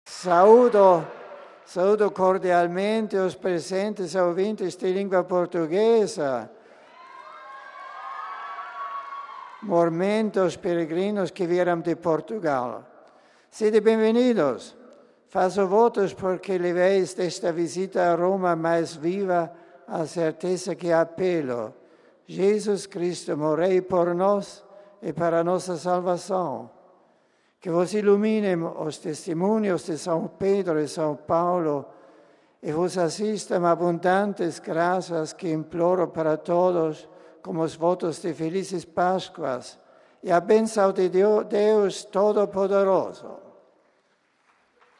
Durante a Audiência na Sala Paulo VI, Bento XVI enfatizou que "precisamente por seu dramático fim, Boécio pode passar sua experiência também ao homem contemporâneo e, sobretudo, às tantas pessoas que sofrem a mesma sorte devido à injustiça presente em tanta parte da justiça humana".
Por fim, o Santo Padre saudou em várias línguas os diversos grupos de fiéis e peregrinos presentes.